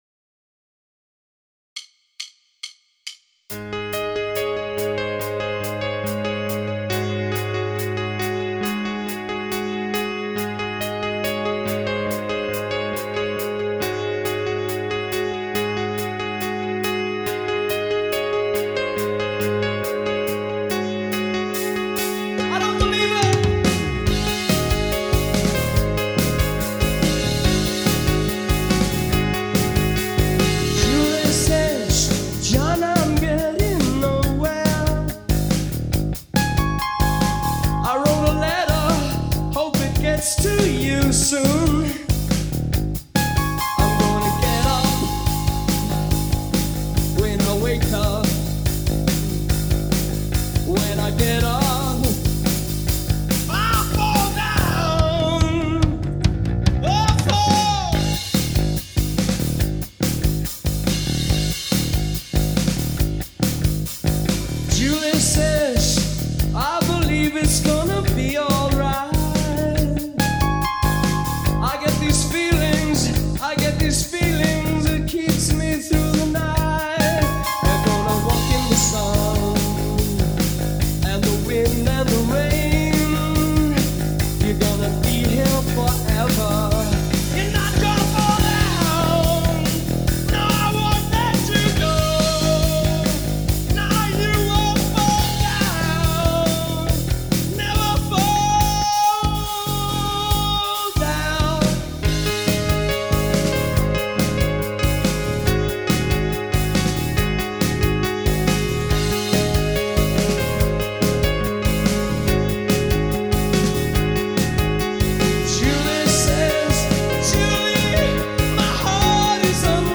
BPM : 141
Tuning : Eb
Without second guitar
With Piano
With vocals
Based on the 1982 Hammersmith Palais live version